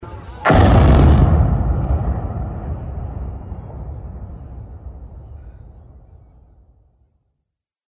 Old Missouri River Bridge on U.S. Highways 14/83 between Fort Pierre and Pierre  goes down with a boom!
It wasn’t an earthquake, a large vehicle running into the train bridge or the 4th of July, but there was a big boom a little after 9am CT Monday (March 17, 2025) in Pierre and Fort Pierre.
That’s the sound of the explosion that brought down the girders that had served as the foundation for the Lt. Cmdr. John C. Waldron Memorial Bridge over the Missouri River between Pierre and Fort Pierre.